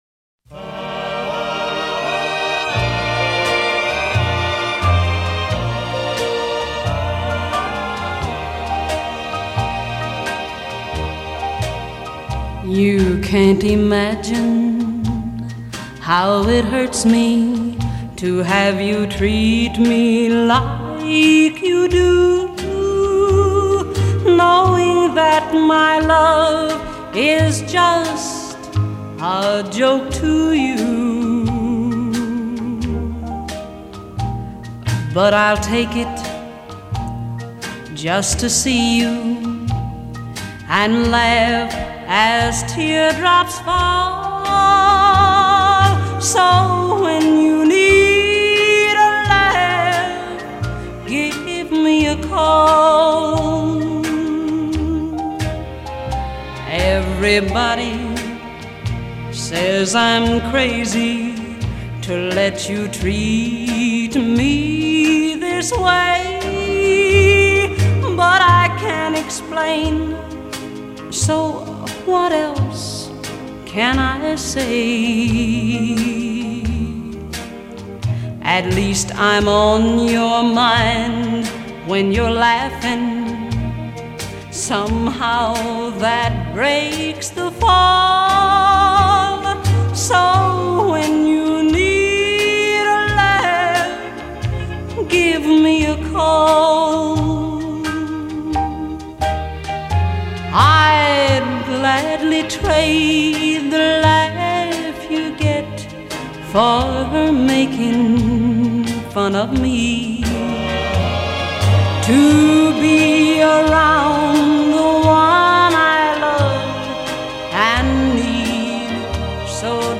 прекрасной певицей
и её весьма приятными и мелодичными композициями.